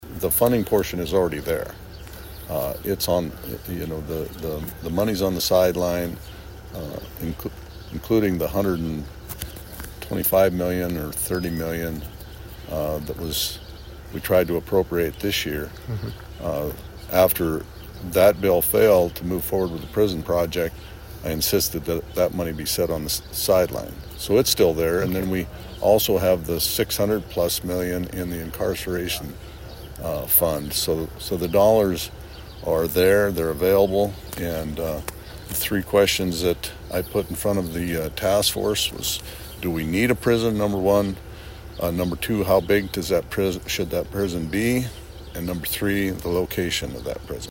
Rhoden discuss the target date he set for a potential special session for July 22nd is flexible and can be changed if needed.